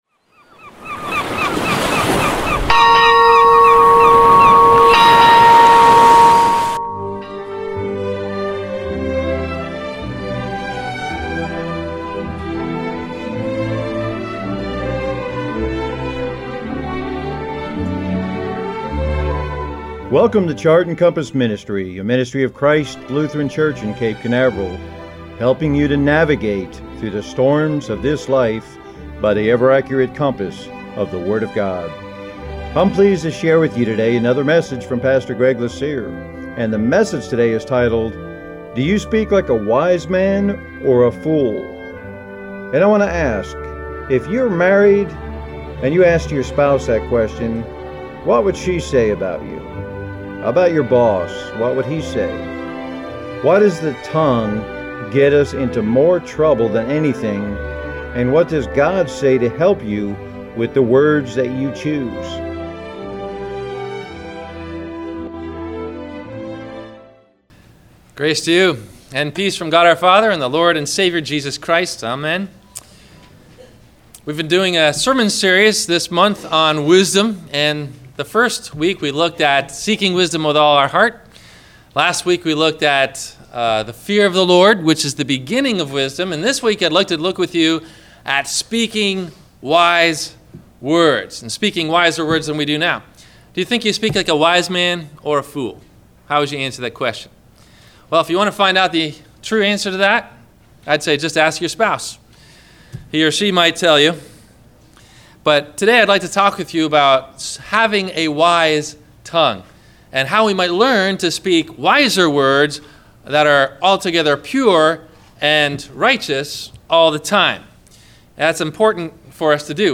Do You Speak Like a Wise Man or a Fool? – WMIE Radio Sermon – May 28 2018